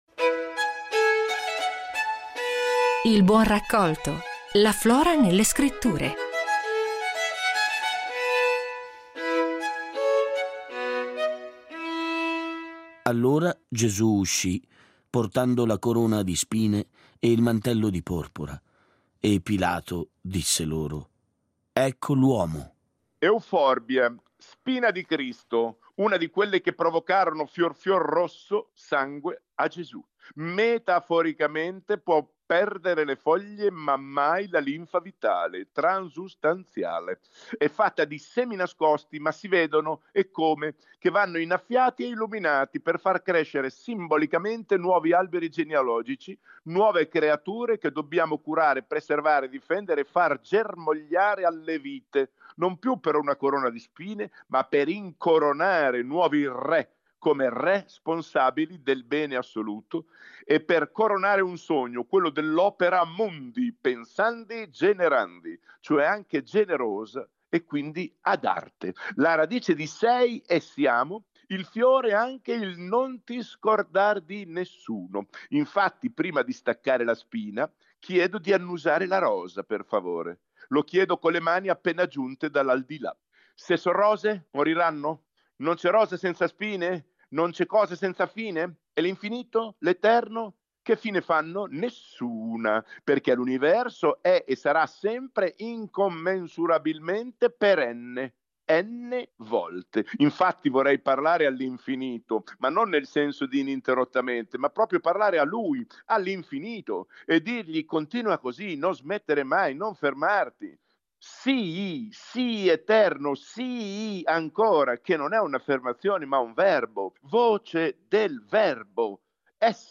Il racconto di Alessandro Bergonzoni
La Spina di Cristo è una pianta così denominata proprio perché simboleggia temi come speranza e rinascita. A raccontarci questo vegetale è lo scrittore, attore e paroliere Alessandro Bergonzoni.